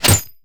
bullet_impact_glass_09.wav